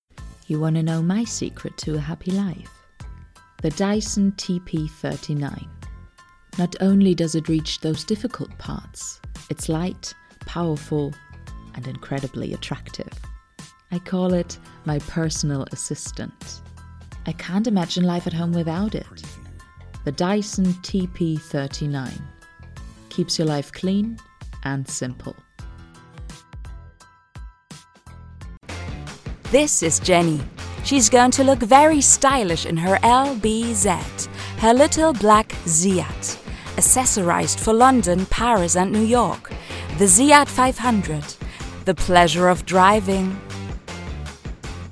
Foreign Language Voice Samples
Commercial Demo
I specialize in bringing characters, commercials, and narrations to life with a warm, engaging, and versatile voice.
I work from my professional home studio, ensuring high-quality recordings, fast turnaround times, and a flexible, reliable service.
LowMezzo-Soprano